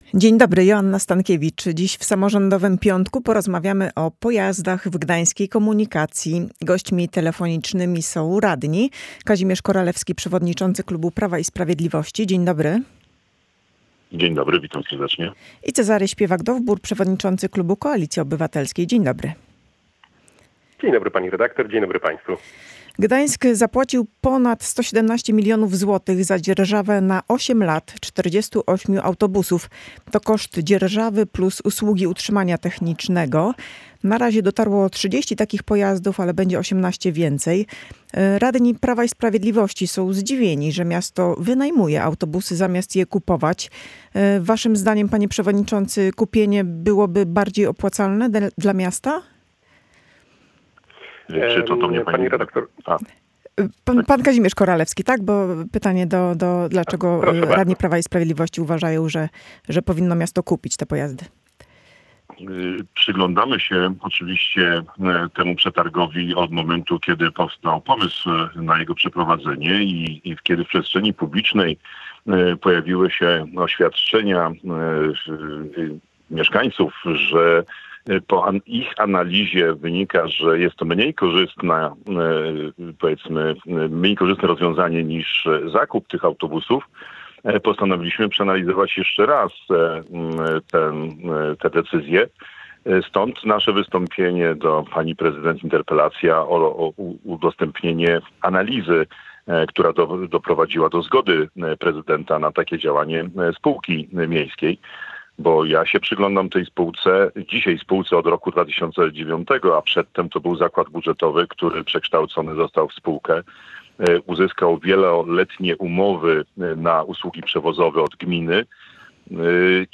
Radni Prawa i Sprawiedliwości i Koalicji Obywatelskiej komentują